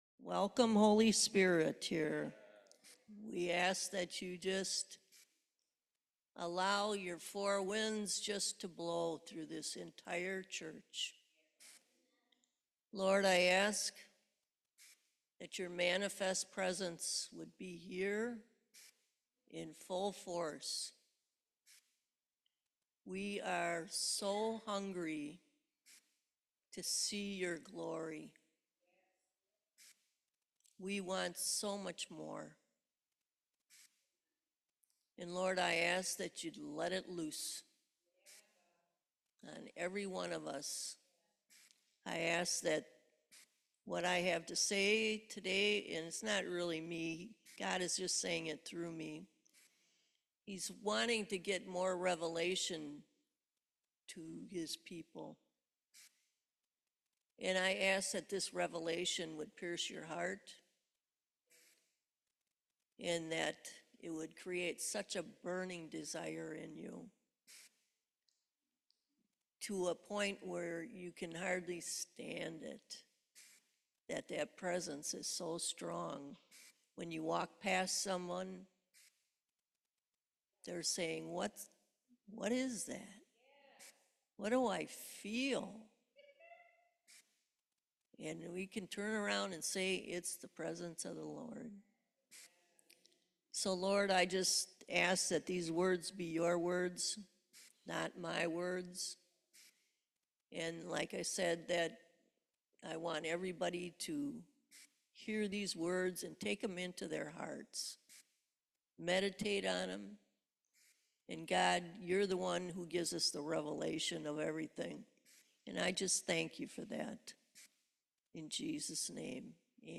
Revelation 19:7-8 Service Type: Main Service Do what the Lord tells you to do.